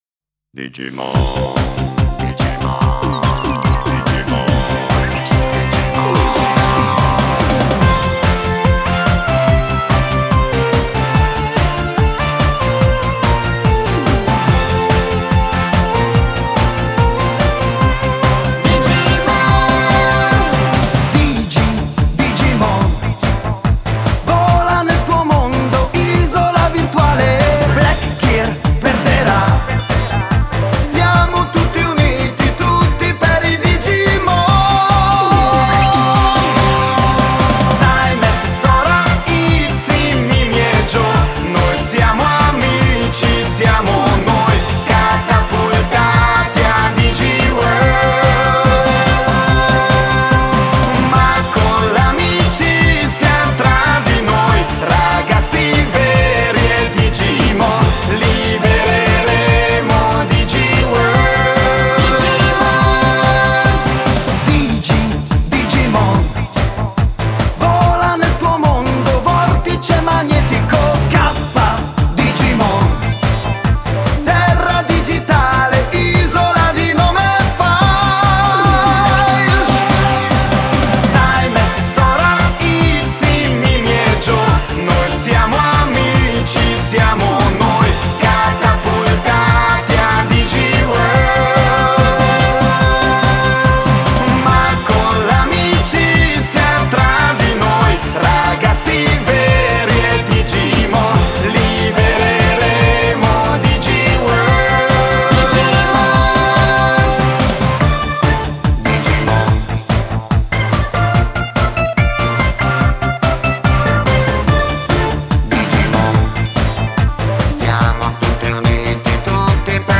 sigla italiana